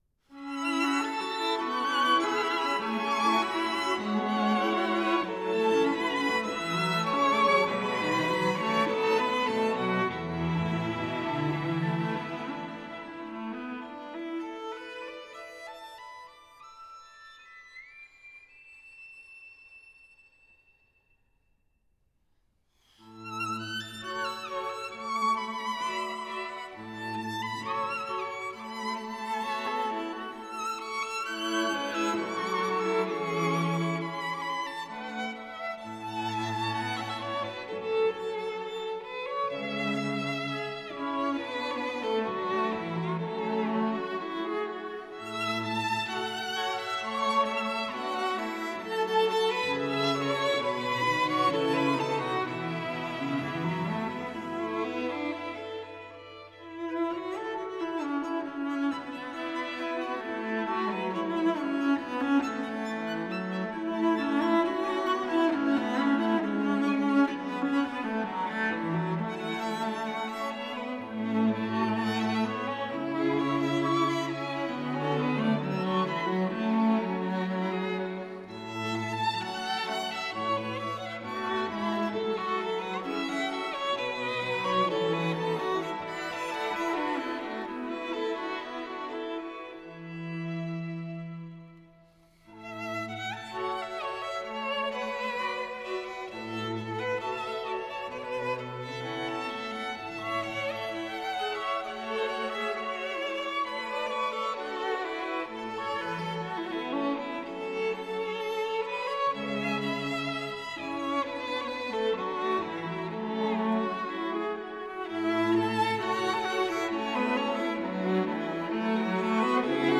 改编自中国民谣的弦乐四重奏